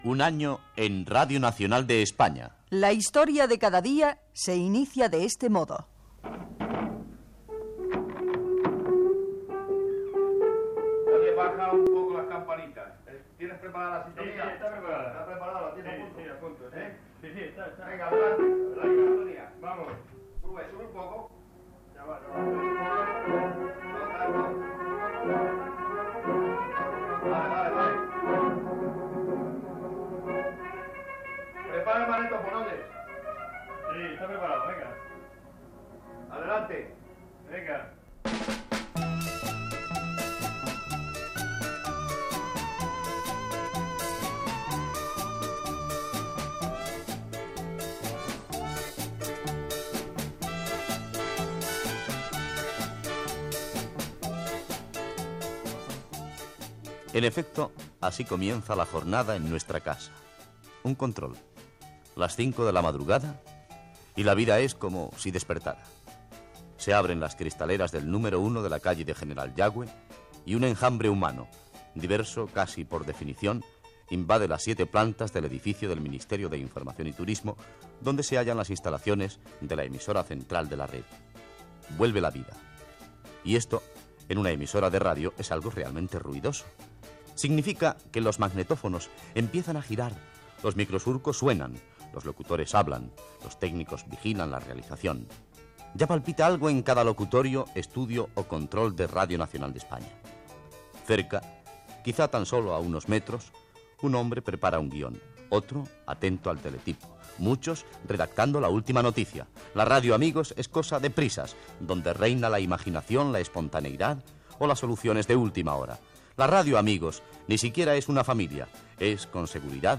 Info-entreteniment
Extret del programa "El sonido de la historia", emès per Radio 5 Todo Noticias el 5 de gener de 2013